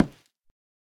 Minecraft Version Minecraft Version snapshot Latest Release | Latest Snapshot snapshot / assets / minecraft / sounds / block / nether_wood / break2.ogg Compare With Compare With Latest Release | Latest Snapshot
break2.ogg